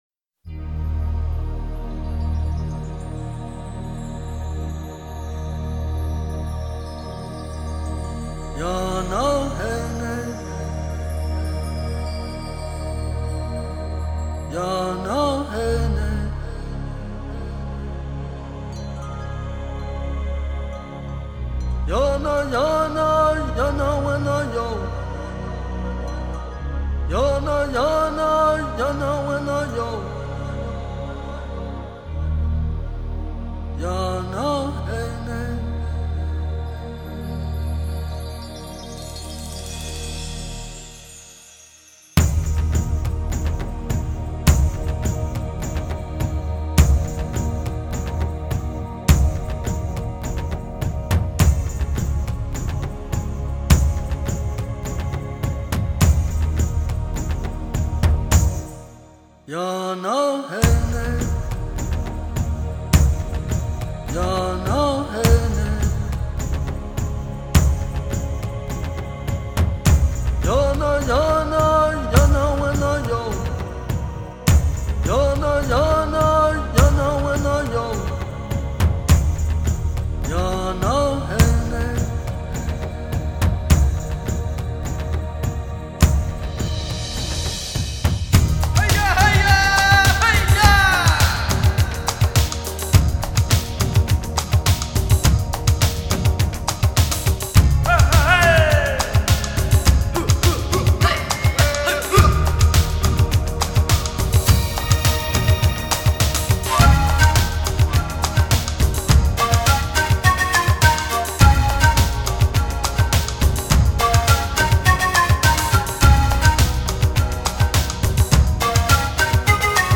融合印度及非洲音乐特色
反复的吟唱，旋律改进后的紧凑和激情，竟然还有我们熟悉的二胡，笛子！